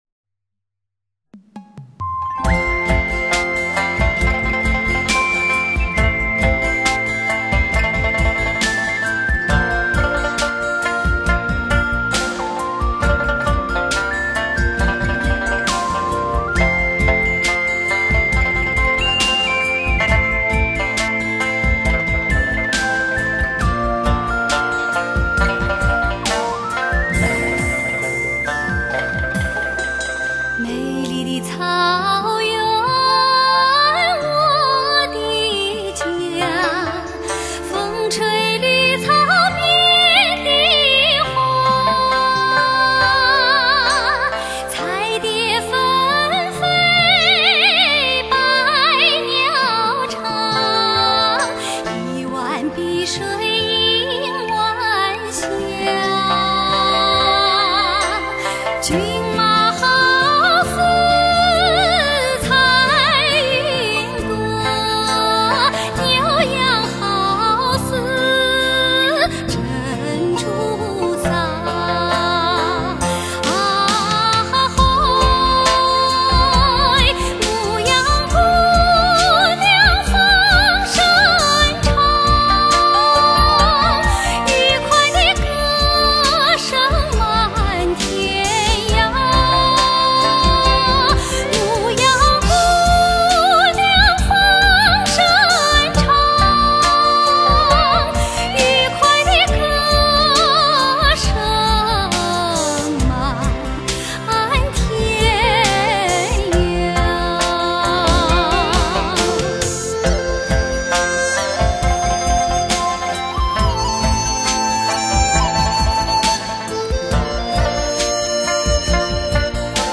经典民谣
乡村民谣节奏元素，爵士、布鲁斯特色、拉丁、华